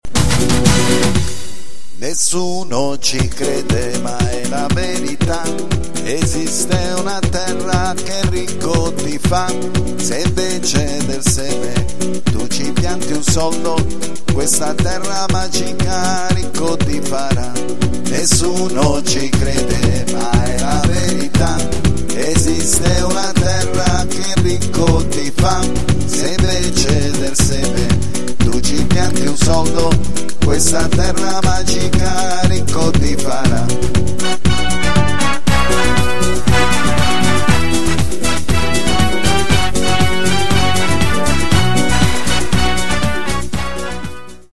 in chiave teatrale  e musicale di
(PARLATO - FORMULA MAGICA SULLA MUSICA)